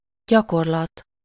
Ääntäminen
IPA: /ˈɟɒkorlɒt/